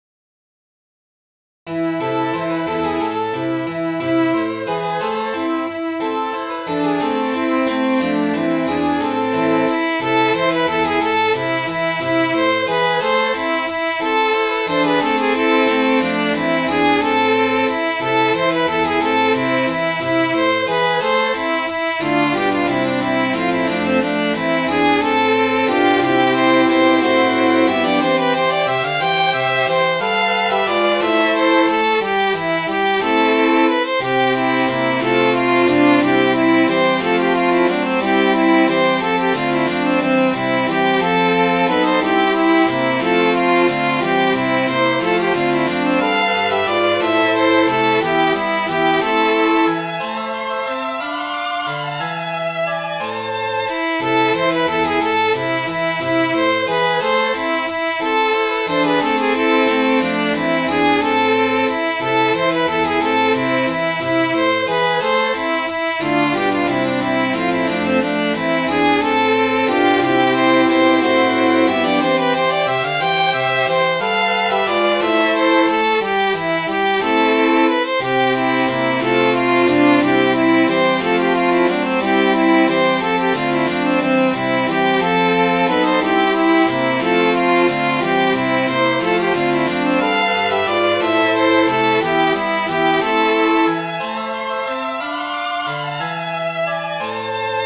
Midi File, Lyrics and Information to Norah, Dear Norah